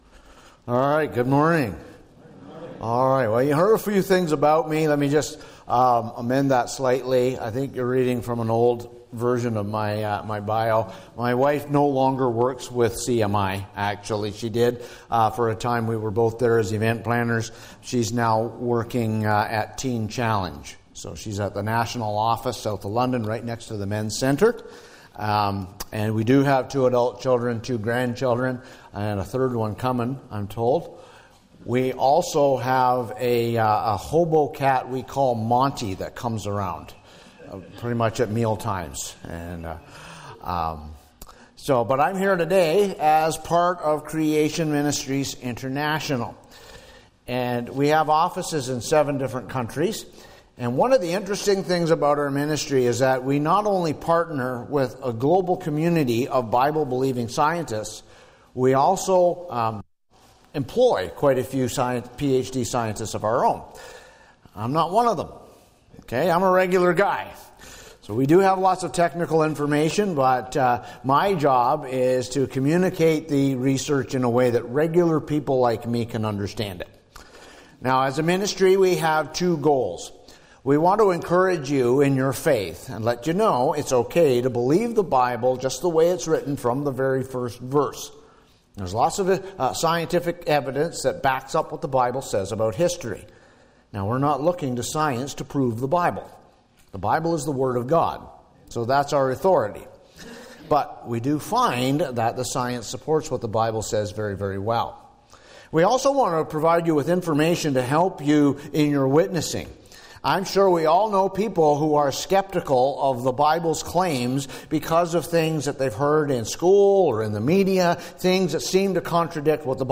Creation Ministries Presentation
Service Type: Family Bible Hour